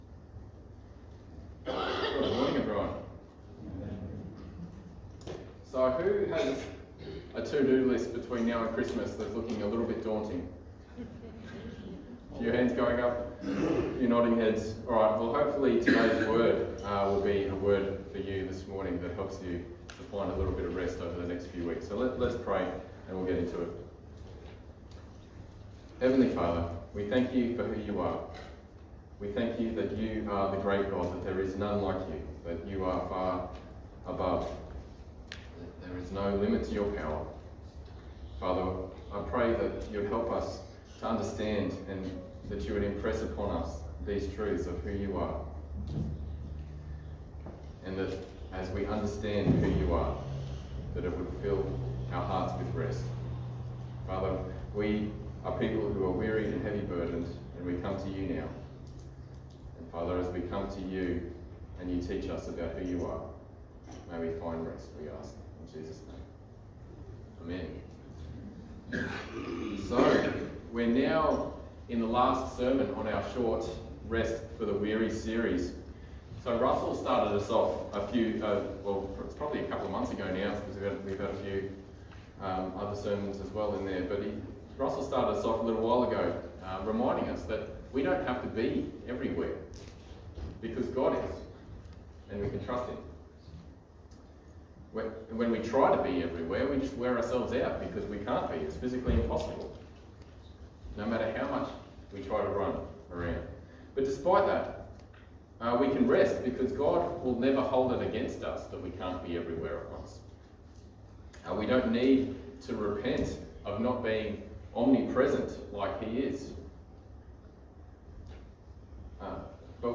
Gympie-Baptist-Church-Live-Stream.mp3